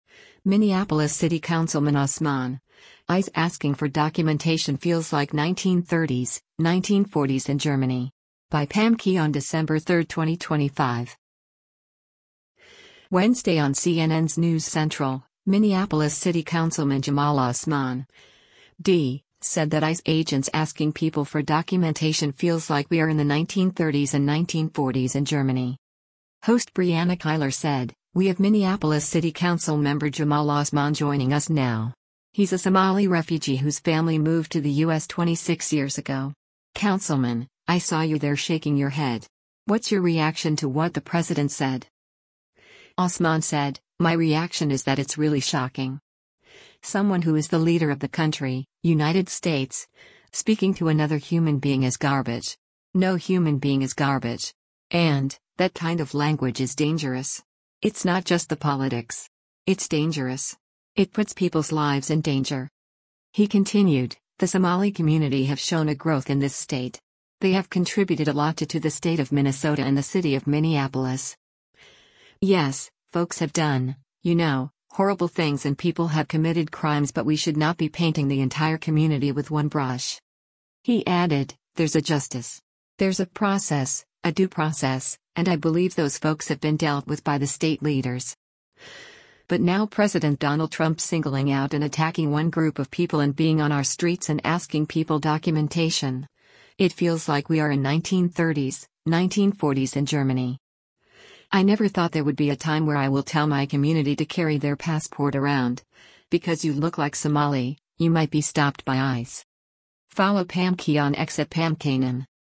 Wednesday on CNN’s “News Central,” Minneapolis City Councilman Jamal Osman (D) said that ICE agents asking people for documentation feels like we are in the 1930s and 1940s in Germany.